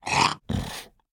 Minecraft Version Minecraft Version snapshot Latest Release | Latest Snapshot snapshot / assets / minecraft / sounds / mob / llama / idle2.ogg Compare With Compare With Latest Release | Latest Snapshot